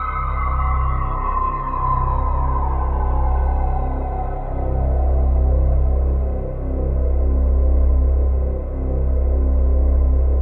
ATMOPAD15 -LR.wav